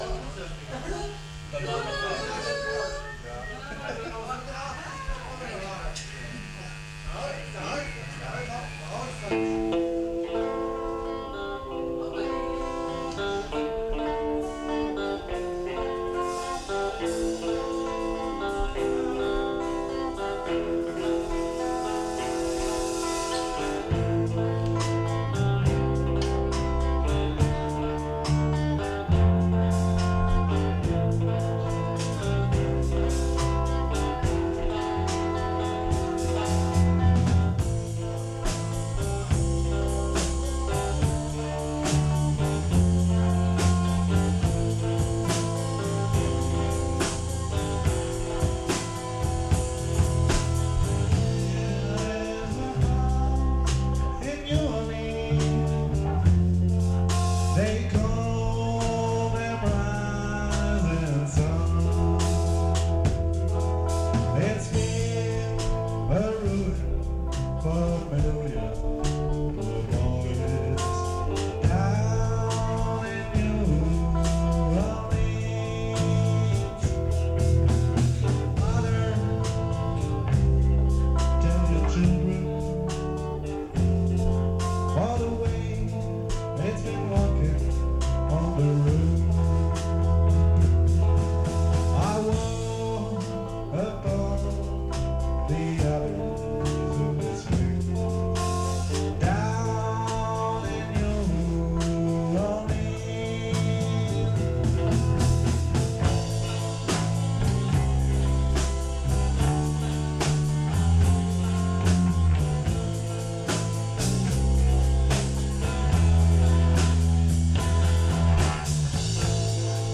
Problem, 2 Drummer, ein Gitarrist, kein Equipment (ausser Drums) Also, einen Bass organisiert mit Amp, eine E-Gitarre mit Amp für Kollege 2, ein Mikro im Gittenamp, fertig.
Text kannte ich nur noch in Fragmenten, also denglisches Kauderwelch.... 4 x geprobt, dann Auftritt vor ca. 30 Leuten am "bunten Abend", das ist das Ergebnis... Wir haben uns 5 Songs draufgeschafft (Schulterklopf) Mitschnitt mit 2 Uher Mikros am HiFi Tape Deck.